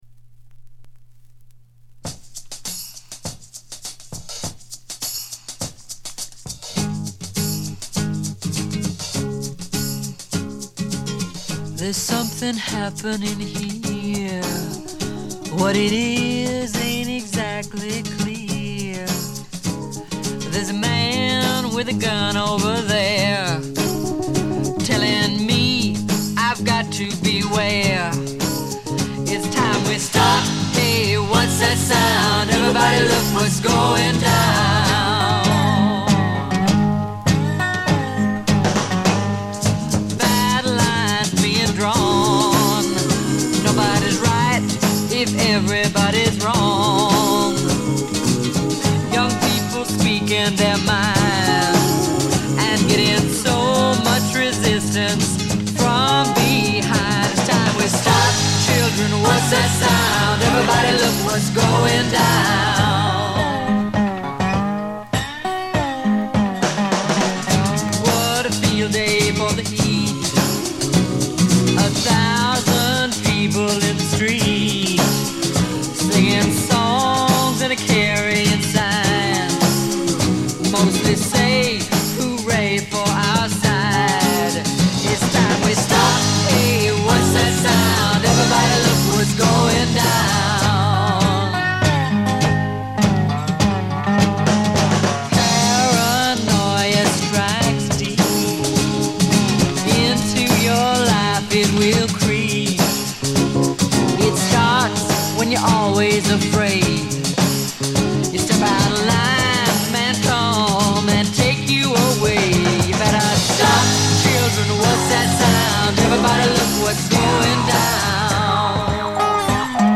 ほとんどノイズ感無し。
白ラベルのプロモ盤。モノ・プレス。
試聴曲は現品からの取り込み音源です。
Recorded At - Muscle Shoals Sound Studios